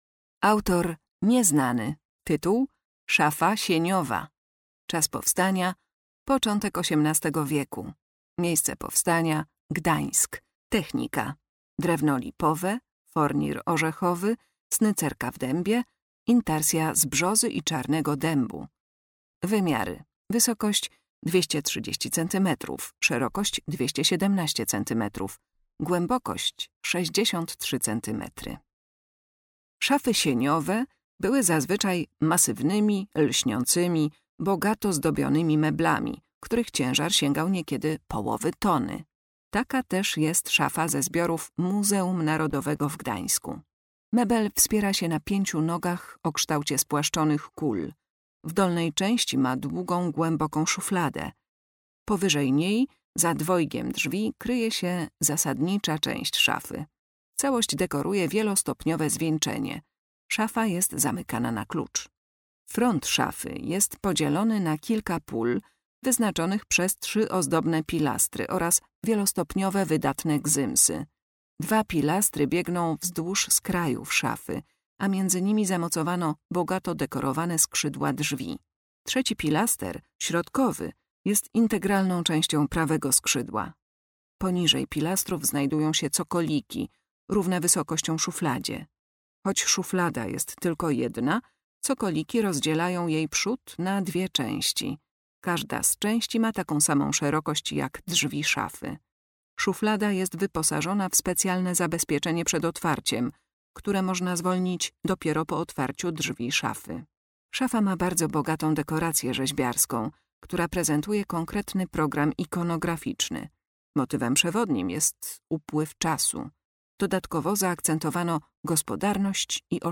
Audiodeskrypcje do wystawy stałej w Oddziale Sztuki Dawnej